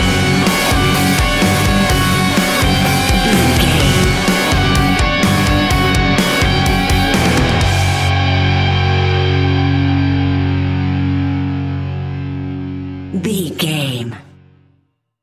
Ionian/Major
F♯
heavy metal
instrumentals